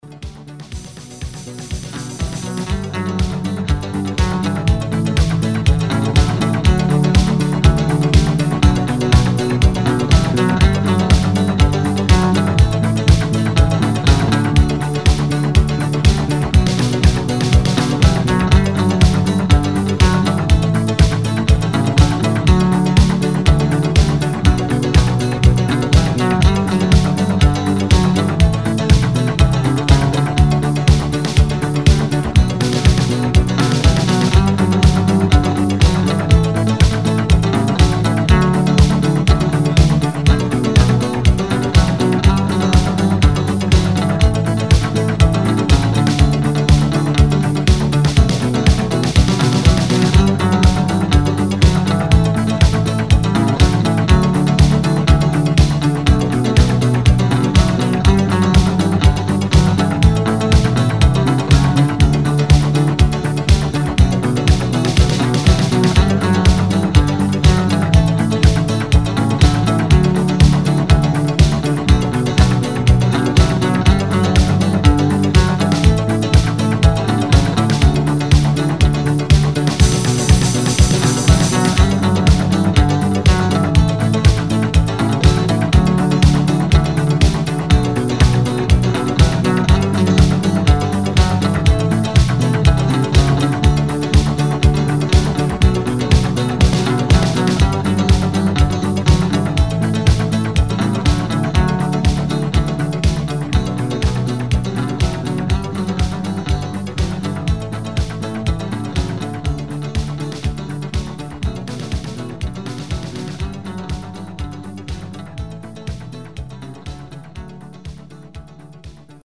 • Качество: 256, Stereo
спокойные
без слов
русский рок
Melodic
Только мелодия!